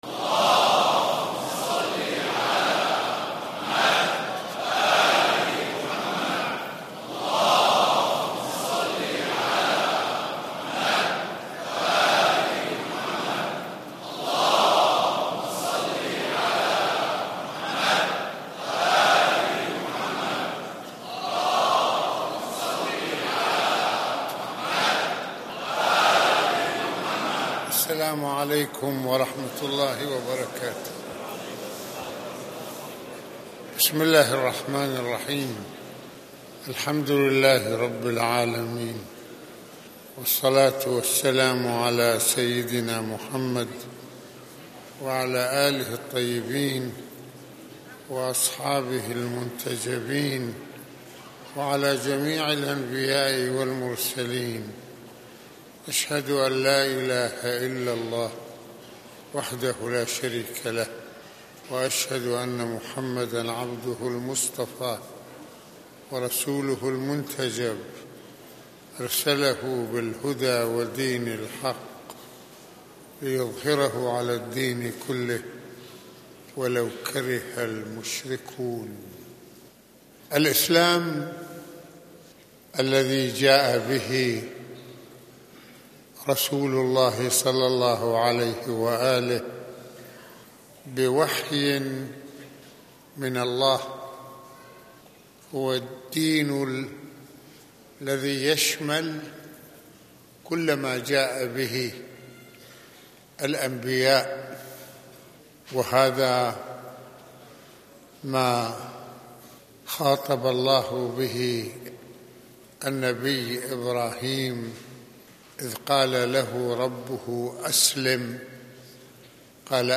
- المناسبة : خطبة الجمعة المكان : مسجد الإمامين الحسنين (ع) المدة : 29د | 58ث المواضيع : الالتزام الفكريّ والعملي بالإسلام - الإسلام دين الرّسالات - شرك الطّاعة - رسالة التّوحيد - التّوحيد العمليّ - العمل قرين الإنسان.